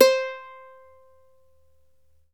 Index of /90_sSampleCDs/E-MU Formula 4000 Series Vol. 4 – Earth Tones/Default Folder/Ukelele
UKE C4-R.wav